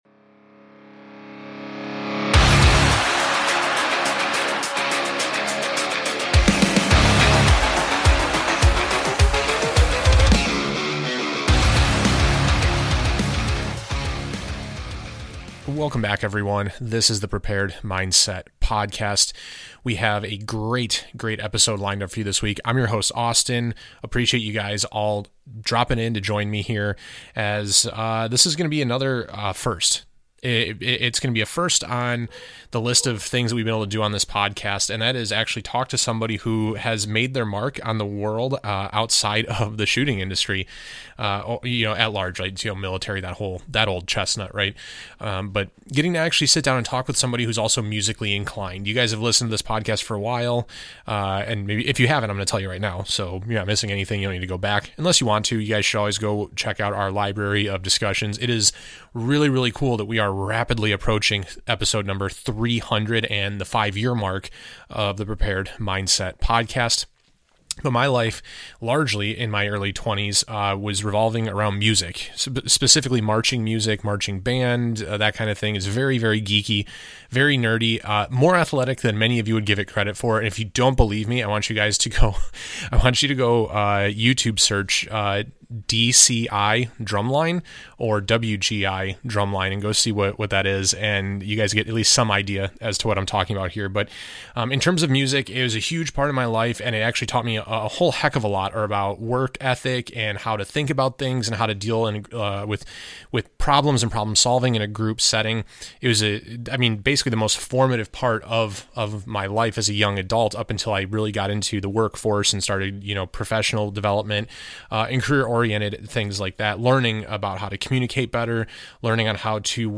The Prepared Mindset Podcast - Episode 289 - Interview with